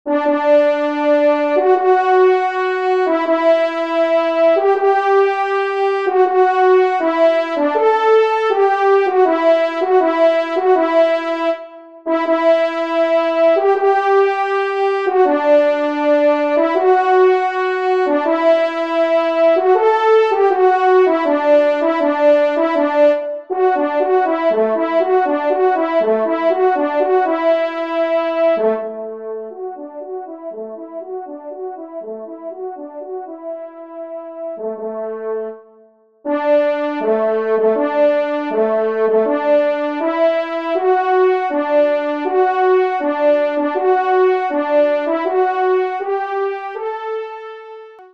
1ère Trompe